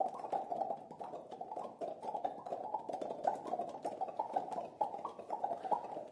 大规模录音根特" 004年手指弹出混乱的T3
描述：声音是在比利时根特的大规模人民录音处录制的。
一切都是由4个麦克风记录，并直接混合成立体声进行录音。每个人都用他们的手指在嘴里发出啪啪的声音。混乱的，没有时间或平移。